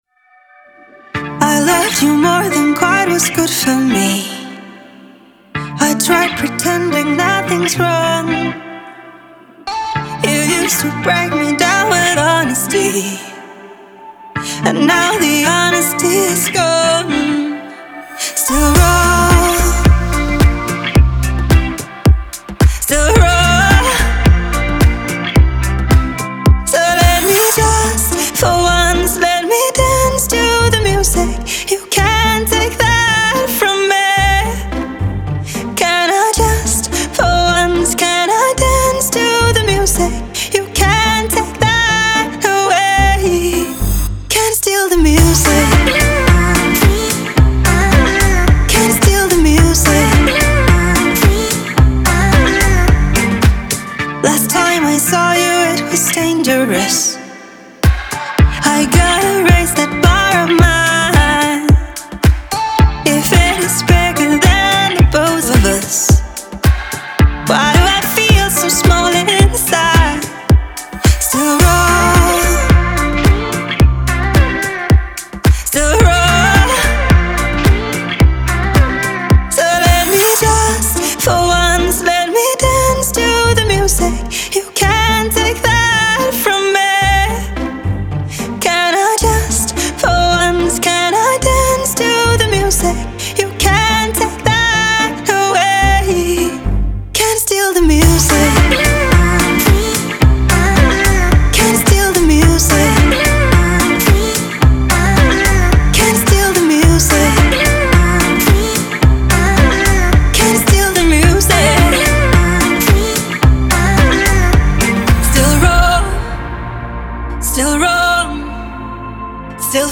丹麦流行女歌手
明媚随性 迷醉女嗓
迷醉女嗓